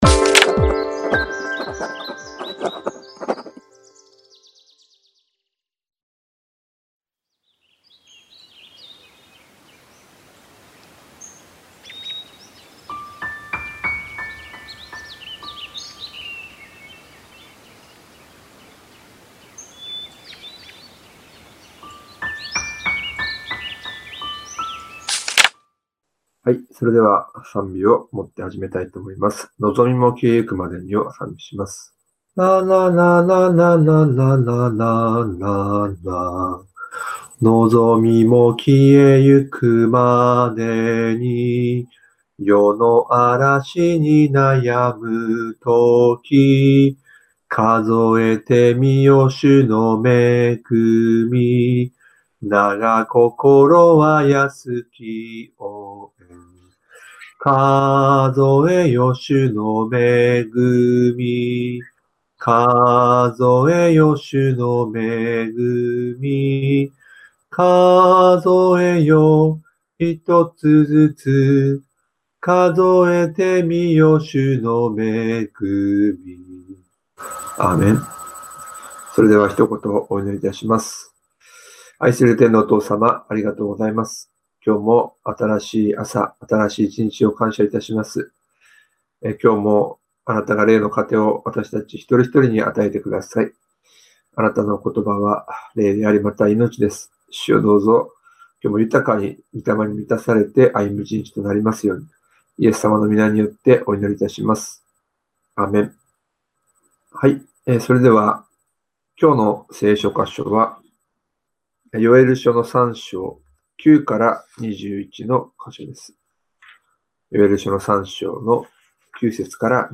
音声版（mp3)←←右クリック「名前を付けてリンク先を保存」で保存できます ※映像と音声が一部乱れている部分がございます。